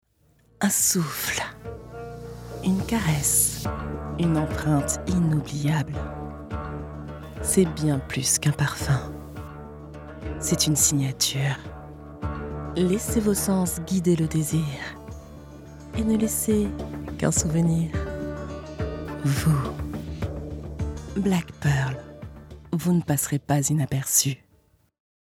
- création originale parfum Black Pearl - voix chuchotée, sensuelle.